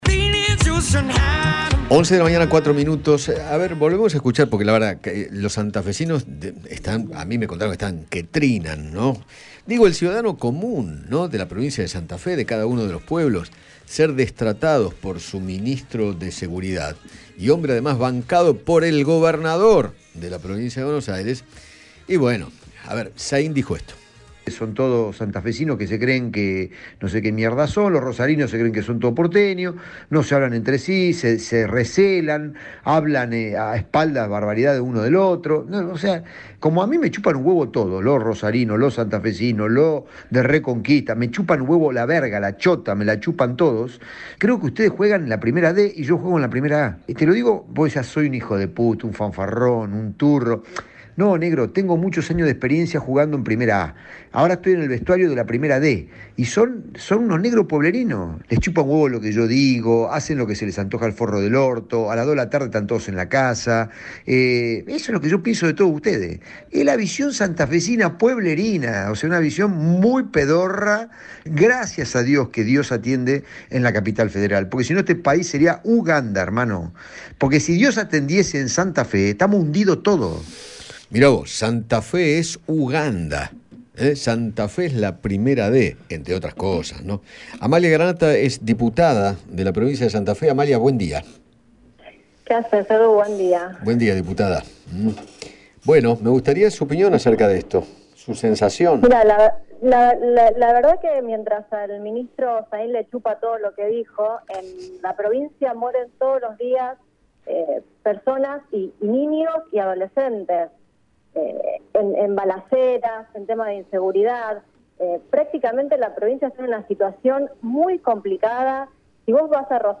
Amalia Granata, diputada provincial de Santa Fe, dialogó con Eduardo Feinmann sobre las declaraciones del ministro de seguridad, Marcelo Sain, y apuntó contra su gestión. Además, se refirió a la complicada situación que atraviesa la provincia con respecto a la inseguridad y expresó que “Rosario hoy es el cartel de Sinaloa”.